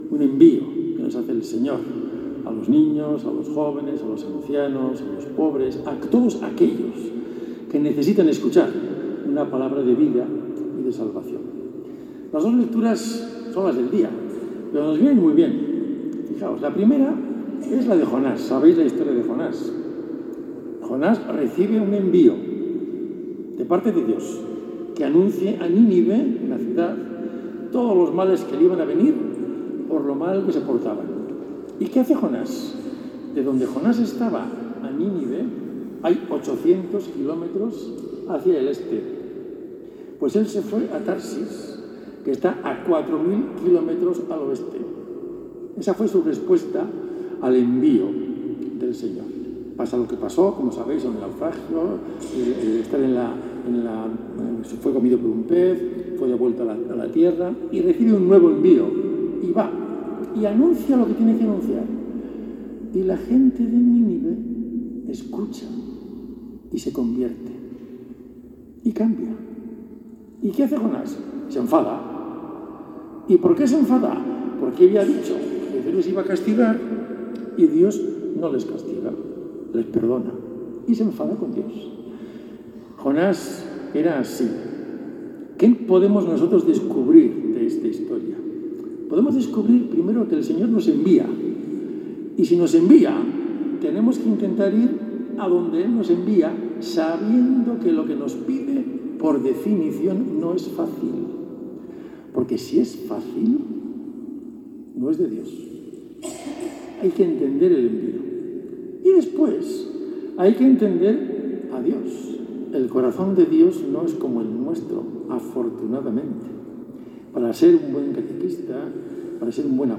La diócesis de Huesca celebró el miércoles 8 de octubre en la catedral el Envío de los agentes de pastoral, una celebración en la que las personas comprometidas con la tarea evangelizadora (catequistas, profesores, personas voluntarias…) reciben el encargo de retomar este curso la actividad pastoral.
El obispo de Huesca, el padre Pedro Aguado Cuesta, presidió la celebración del Envío con una homilía centrada en el sentido profundo de la misión cristiana.